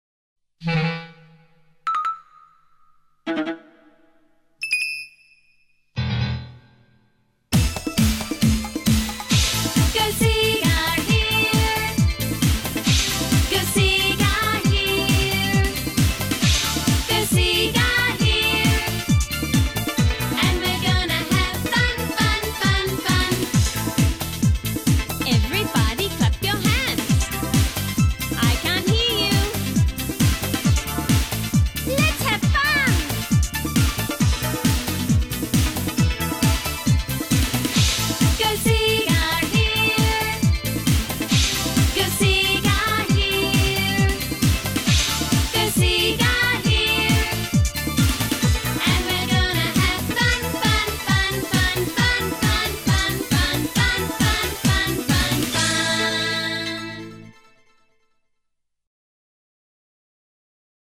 Genre: Children.